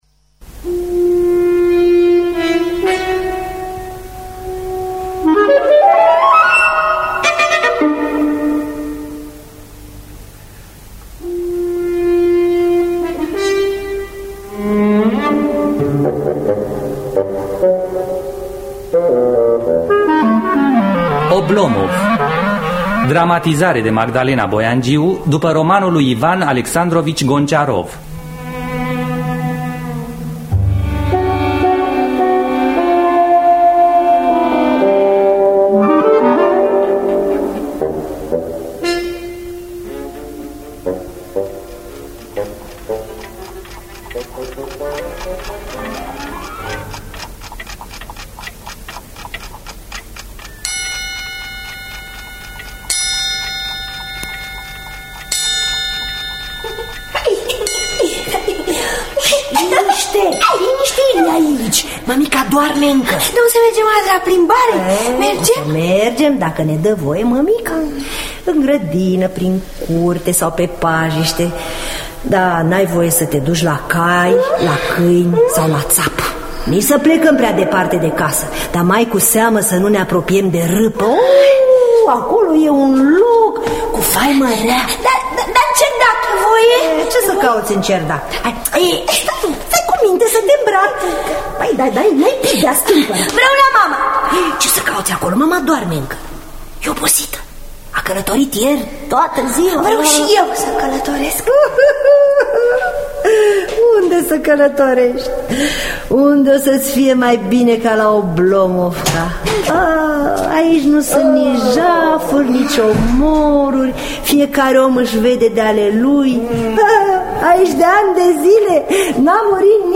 Dramatizarea radiofonică : Magdalena Boiangiu.